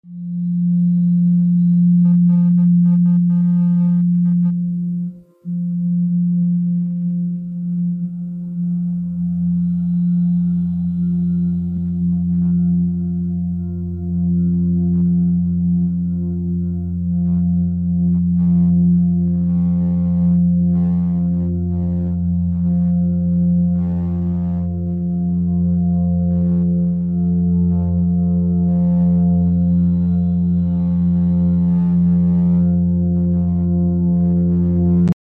DMN هي شبكة دماغية تخليك تسرح وتفقد تركيزك جرّب هذا التردد يخفف الضجة ويرجع تركيزك